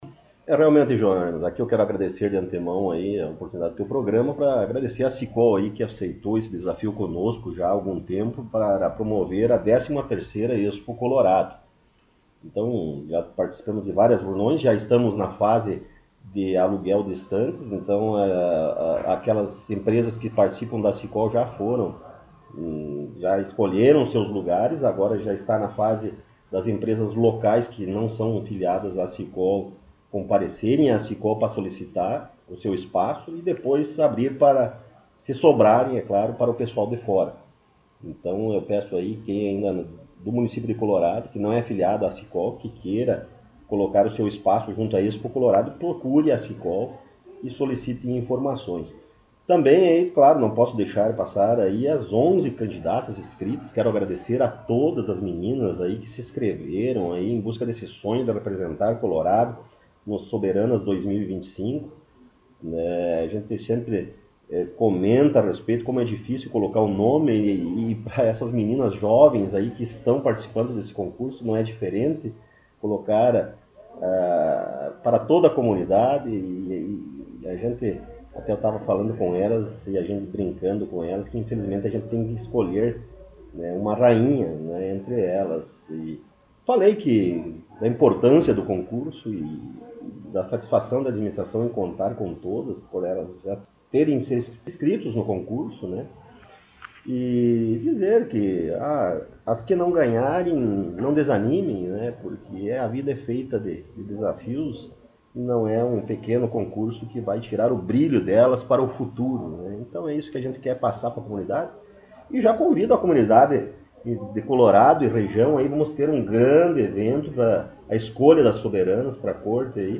Prefeito Rodrigo Sartori concedeu entrevista